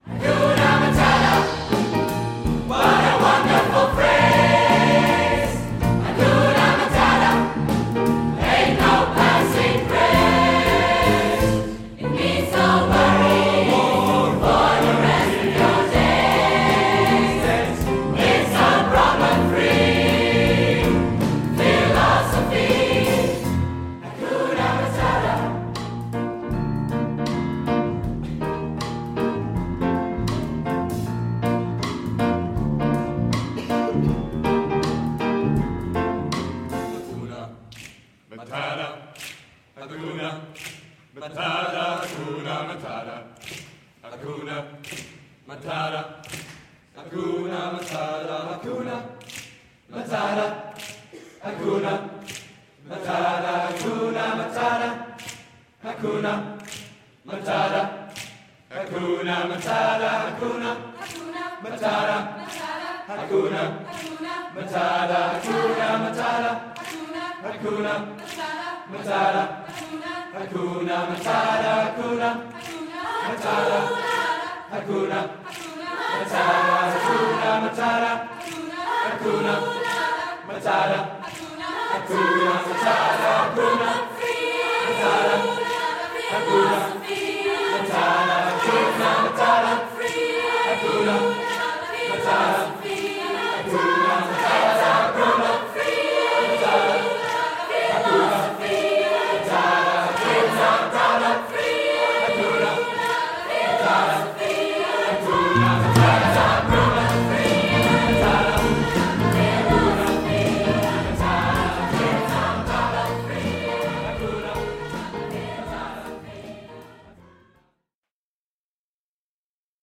Band
SMATB